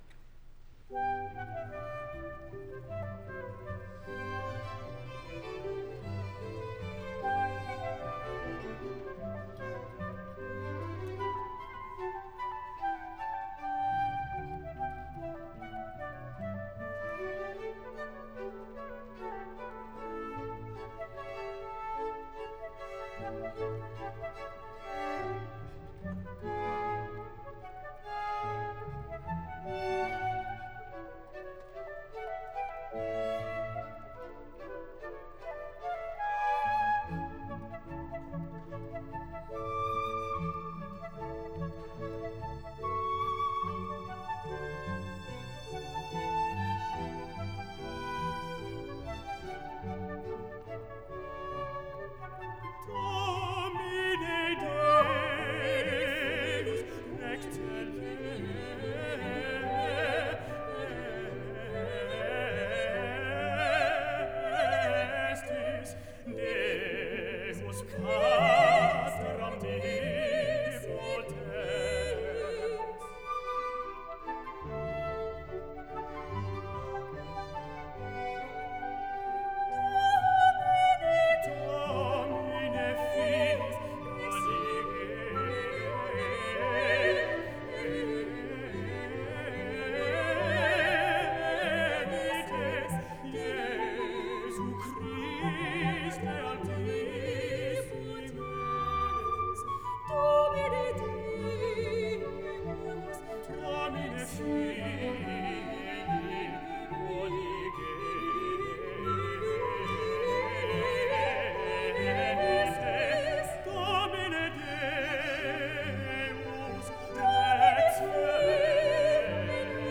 soprano
Duets:
tenor & Simon Carrington